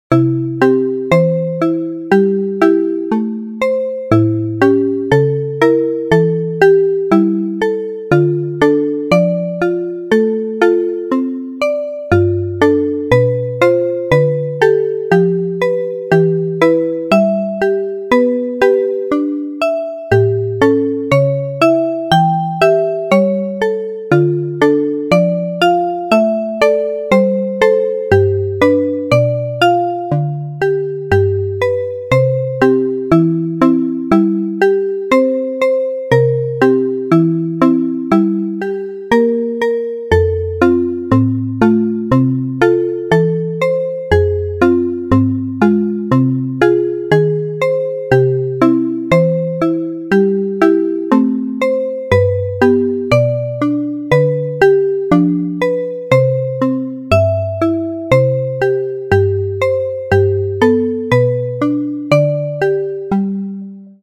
ゆったりした曲です。ループ対応。
BPM60